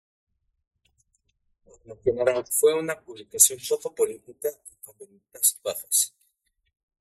ba‧jas
/ˈbaxas/